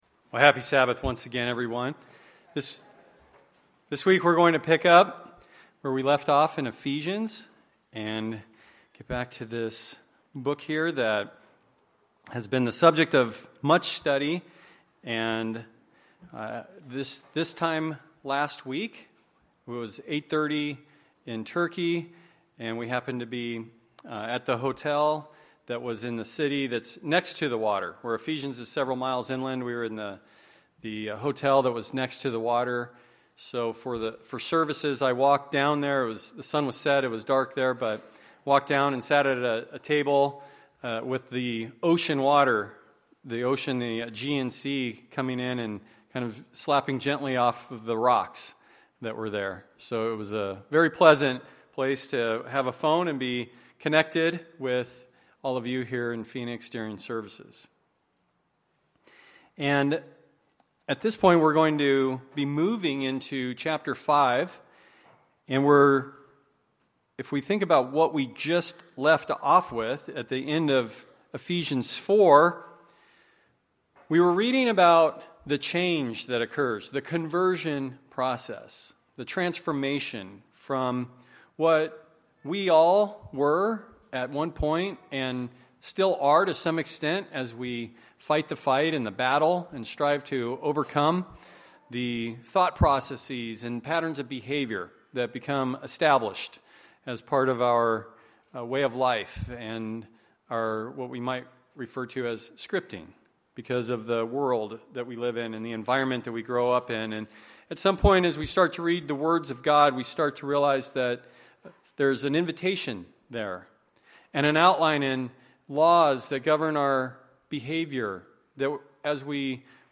Sermons
Given in Phoenix Northwest, AZ